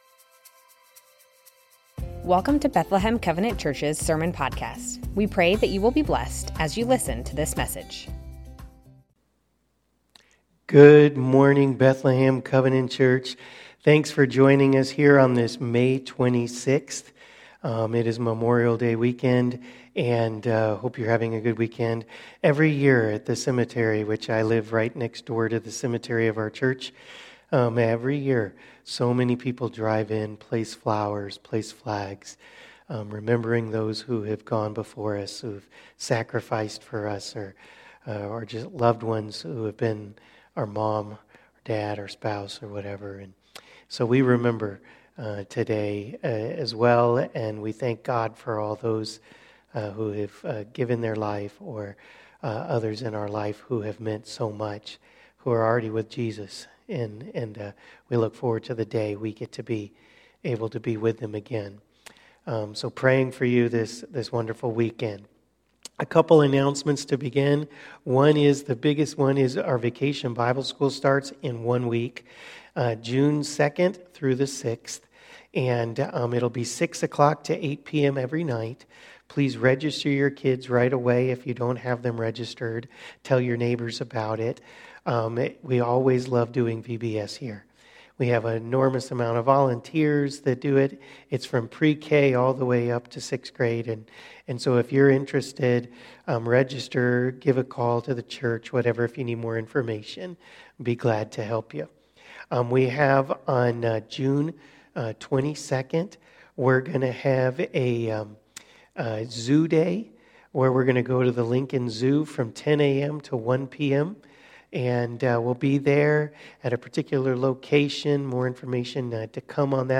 Bethlehem Covenant Church Sermons James: Faith and Works May 26 2024 | 00:32:54 1x 00:00 / 00:32:54 Subscribe Share Spotify RSS Feed Share Link Embed